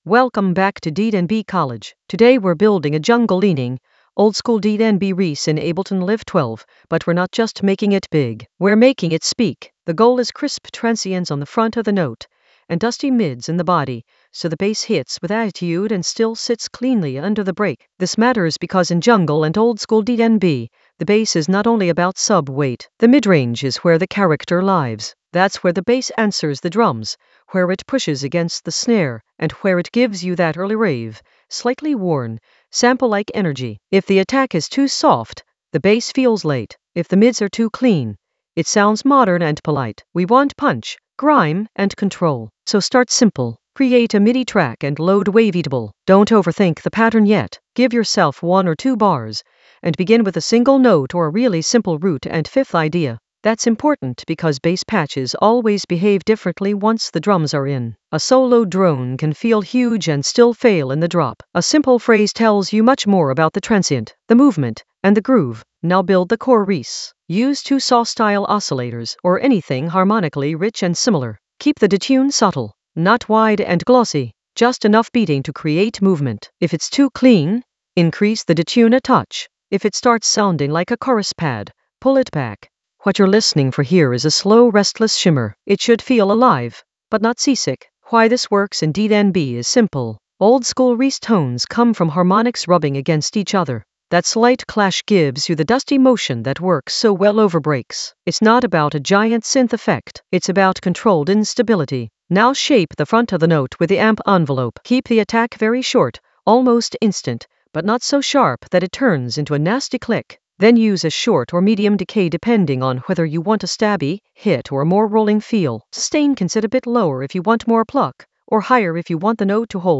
An AI-generated beginner Ableton lesson focused on Flip a reese patch with crisp transients and dusty mids in Ableton Live 12 for jungle oldskool DnB vibes in the Workflow area of drum and bass production.
Narrated lesson audio
The voice track includes the tutorial plus extra teacher commentary.